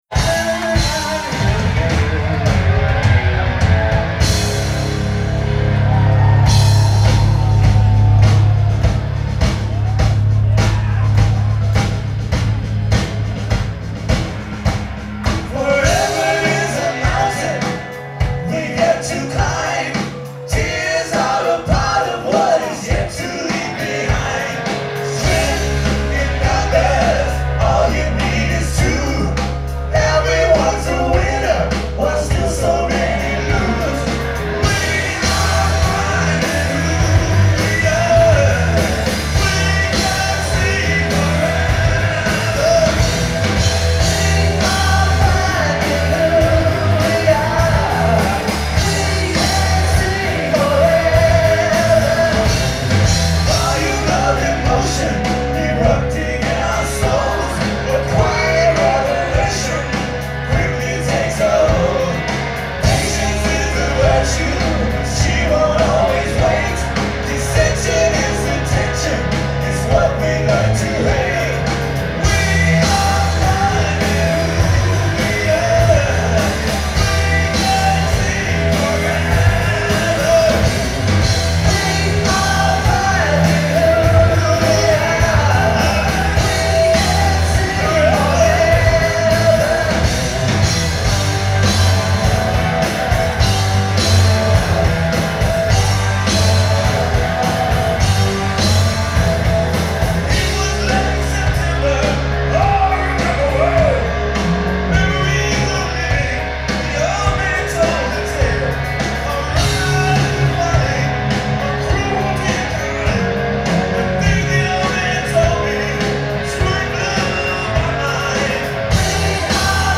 drummer
free concert download